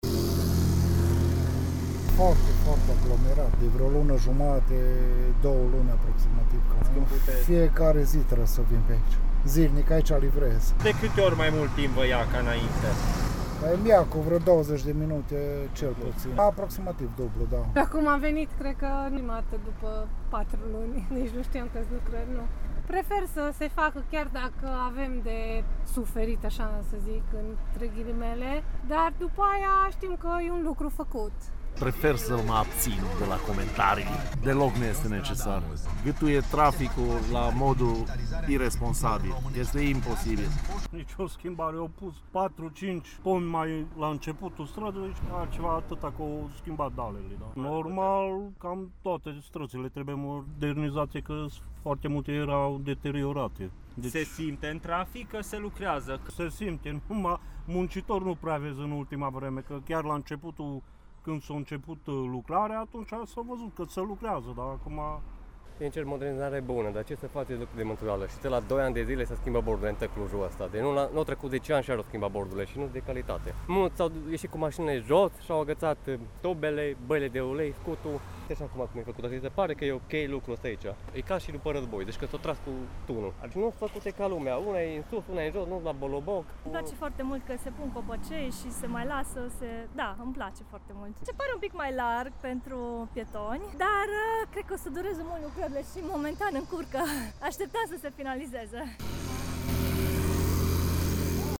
Puteți asculta mai jos declarațiile mai multor șoferi și pietoni vizavi de lucrările de pe strada Iuliu Hossu: